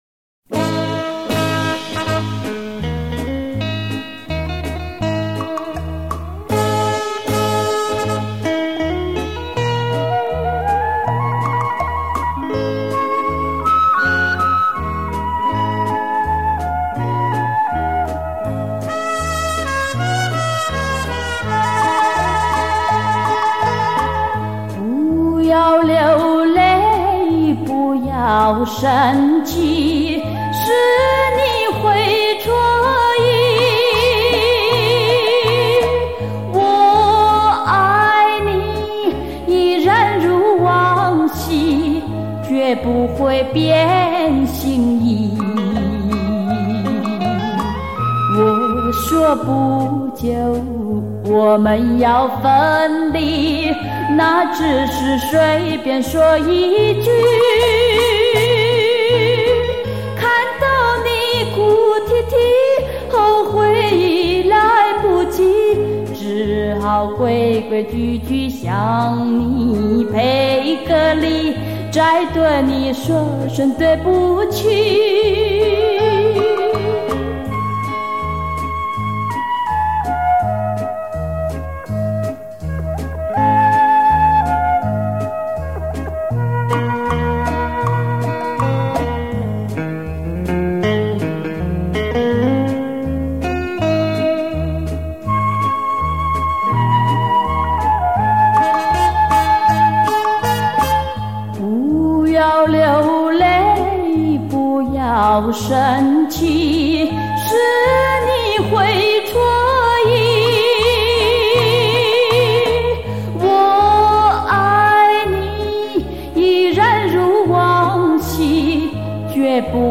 冻结催人岁月 还原昔日情衷 澄净歌乐音质 反刍最初感动
●本专辑录音采用dcs 954机，经24bit Format特别处理
加上128倍超频取样，呈现最精密细致的音频讯号CD录音